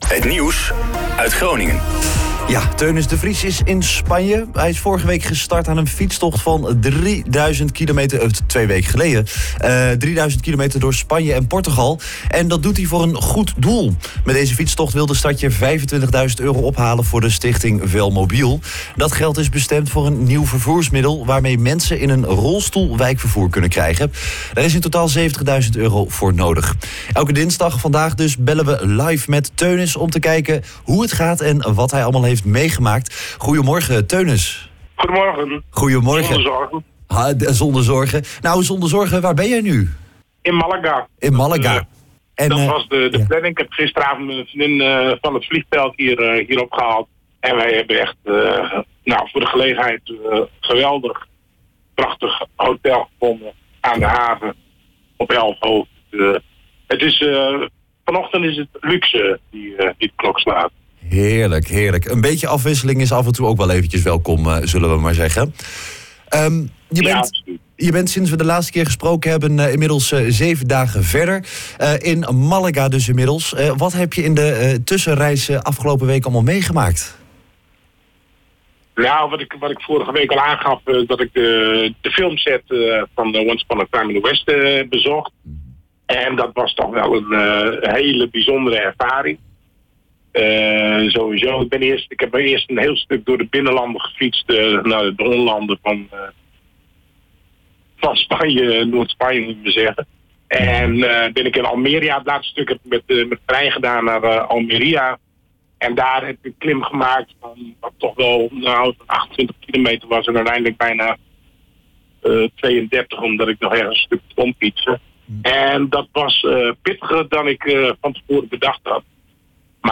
meldt zich dinsdagochtend vanuit een hotelkamer in Málaga met een mooi uitzicht op de haven.